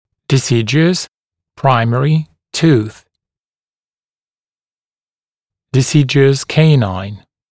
[dɪ’sɪdjuəs tuːθ] [‘praɪmərɪ tuːθ][ди’сидйуэс ту:с] [‘праймэри ту:с]молочный зуб